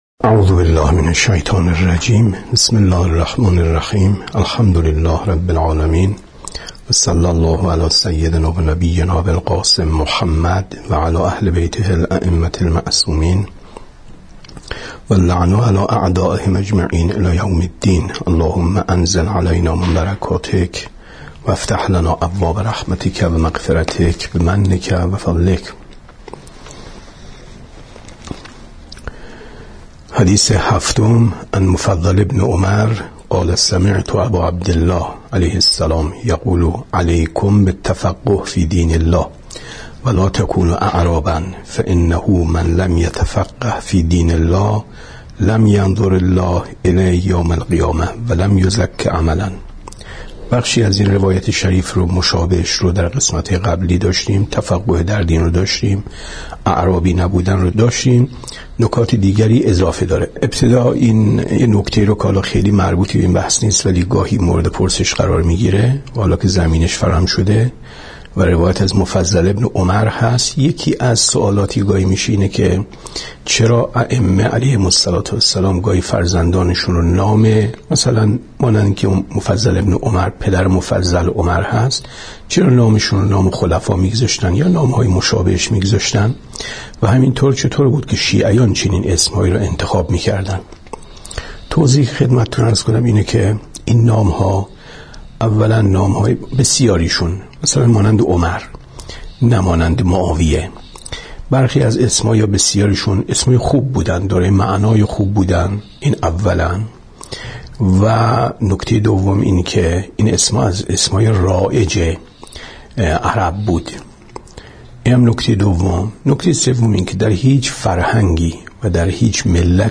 بیانات استاد